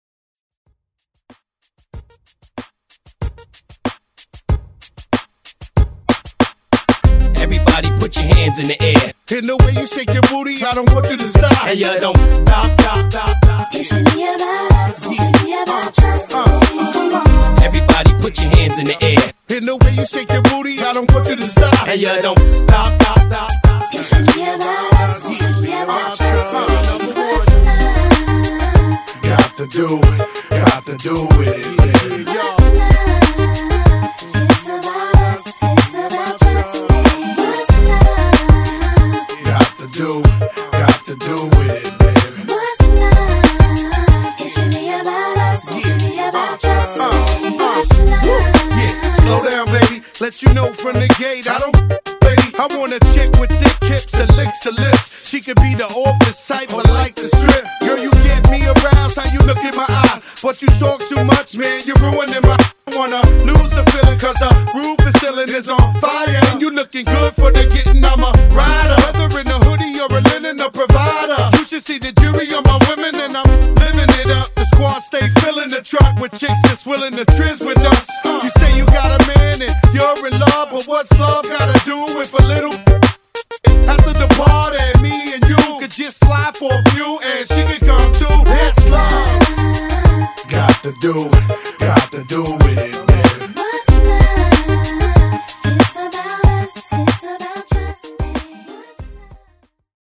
BPM: 94 Time